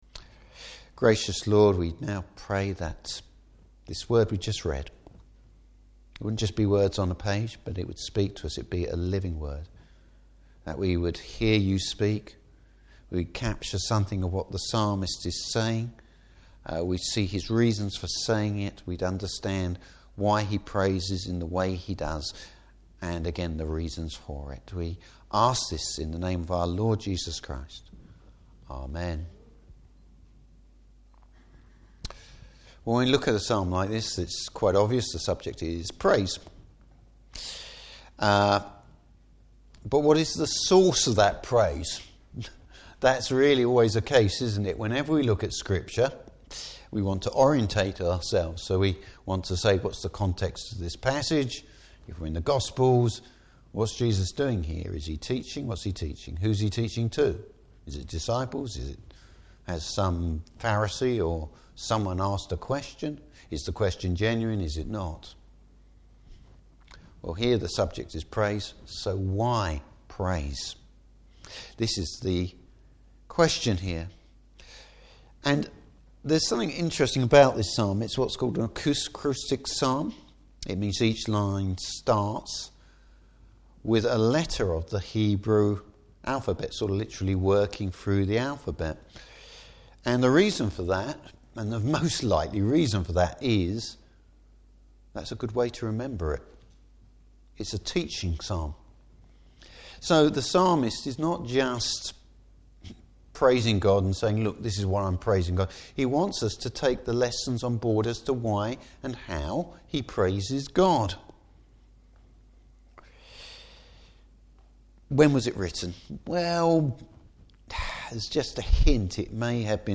Passage: Psalm 111. Service Type: Evening Service A place and time for praising God.